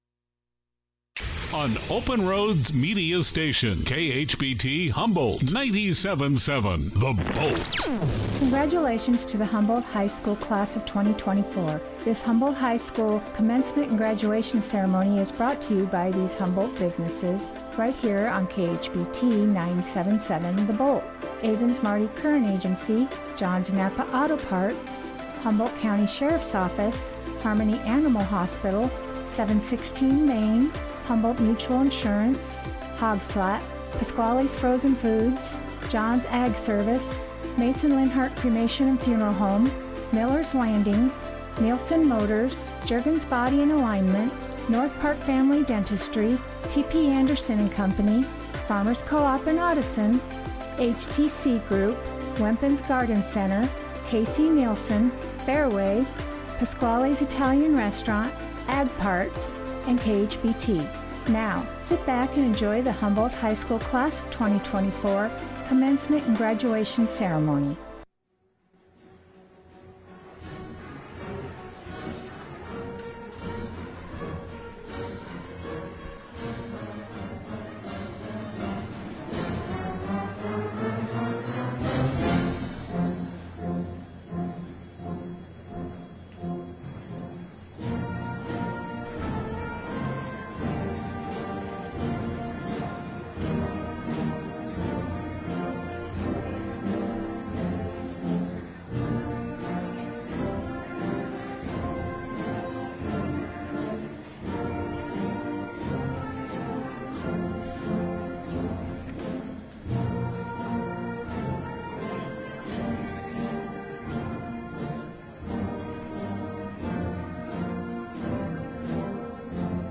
Archived recording of the Class of 2024 Graduation for Humboldt High School.